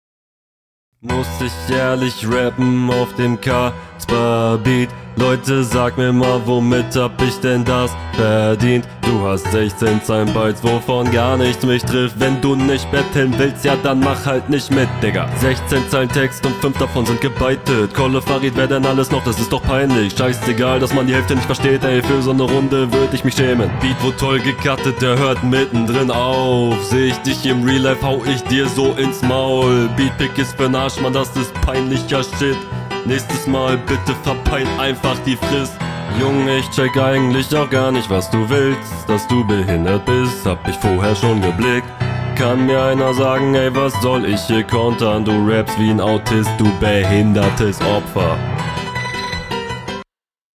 Flowlich stinkt das halt im Kontrast total ab.
Flowlich nicht so stark wie dein Gegner, aber dennoch stark.